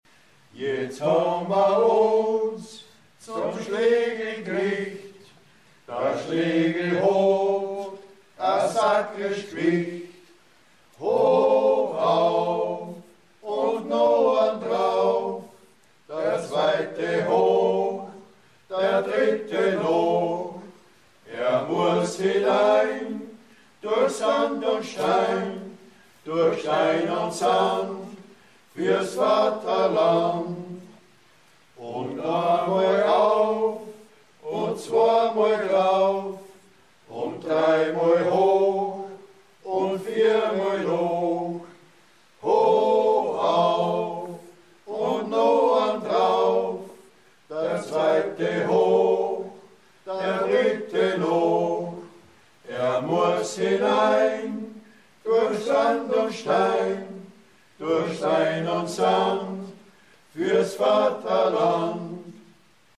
(Texte und Gesangsproben).
Jetzt habn ma uns (Liedertafel Gusswerk)